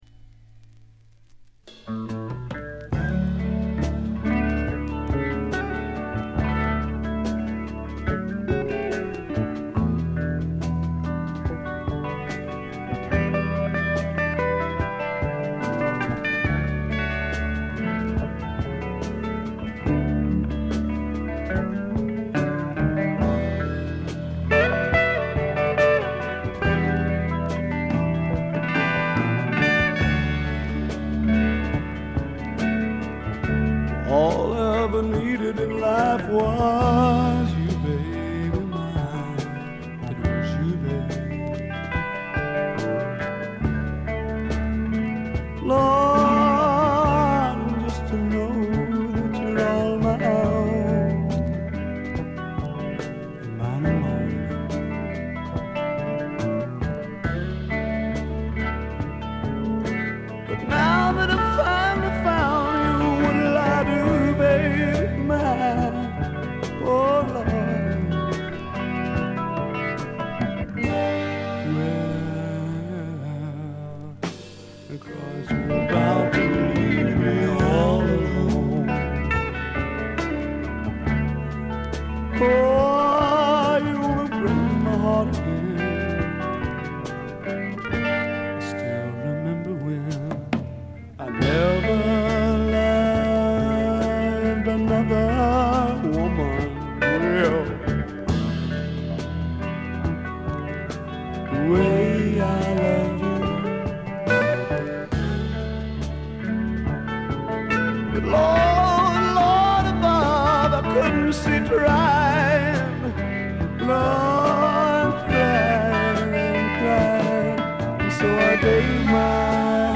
濃厚なスワンプ味に脳天直撃される傑作です。
試聴曲は現品からの取り込み音源です。